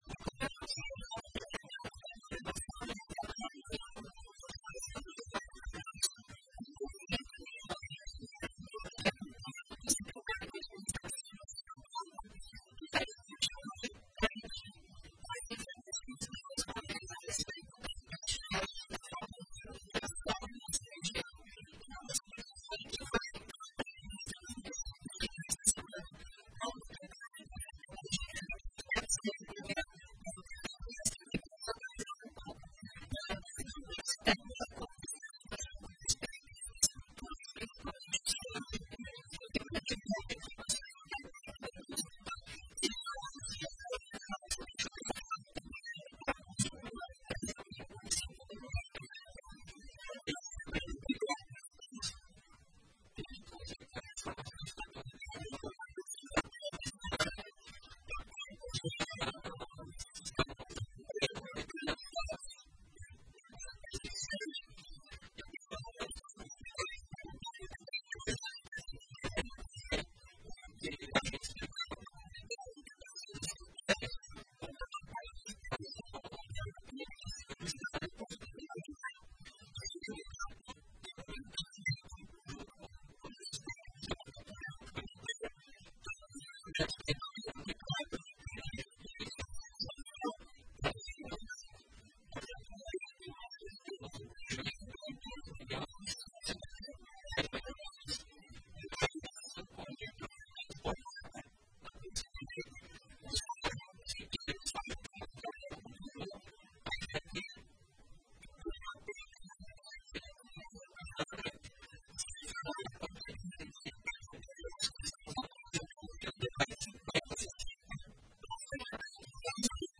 O município de Jóia vai decretar situação de emergência amanhã (07) devido aos prejuízos causados pela falta de chuva. A informação foi confirmada em entrevista à Rádio Progresso nesta manhã (06), pelo prefeito Dionei Levandowski.